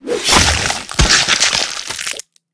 slash.ogg